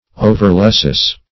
Overluscious \O"ver*lus"cious\, a. Excessively luscious.